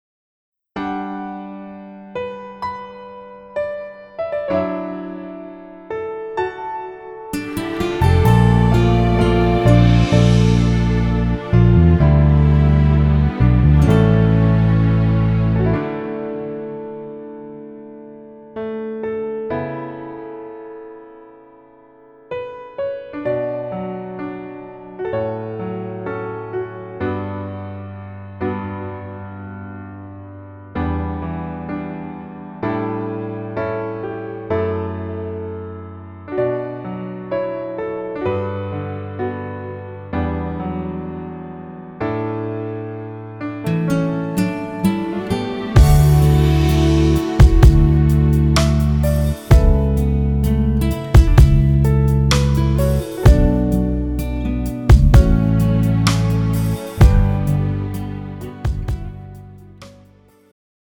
음정 원키
장르 축가 구분 Pro MR